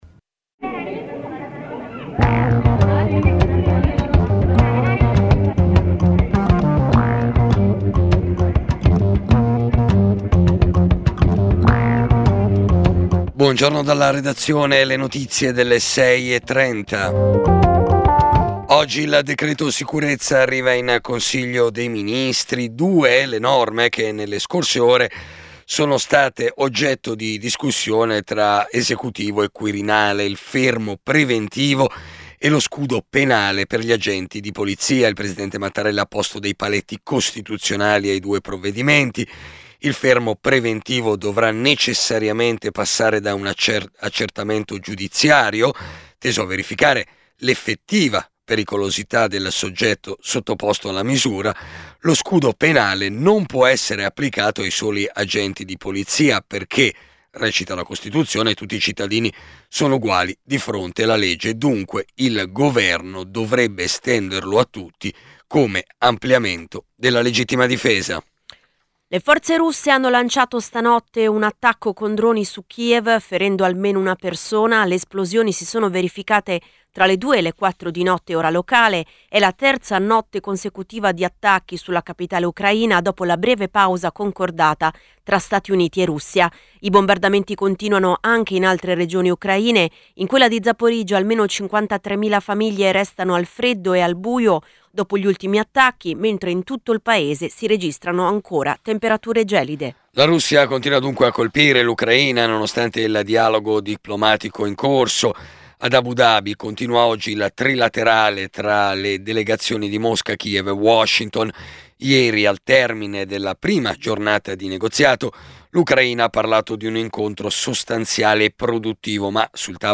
Giornale radio Nazionale